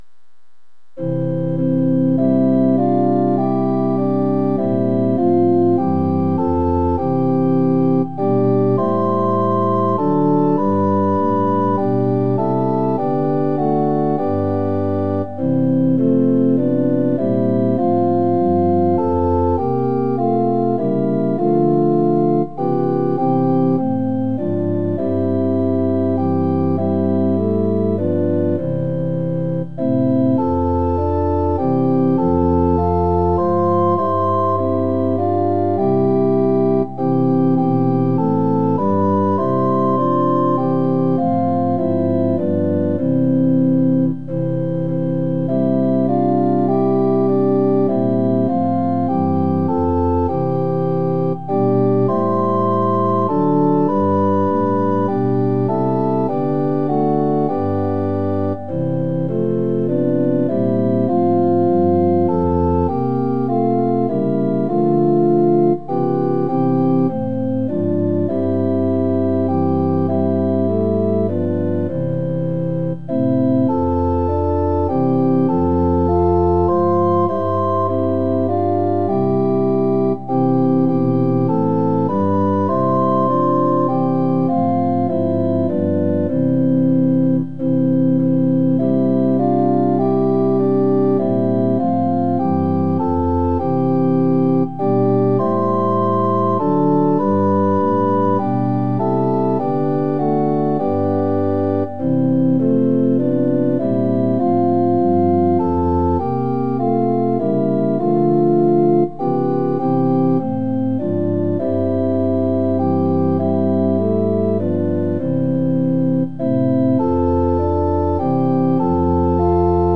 ◆　４分の３拍子：　３拍目から始まります。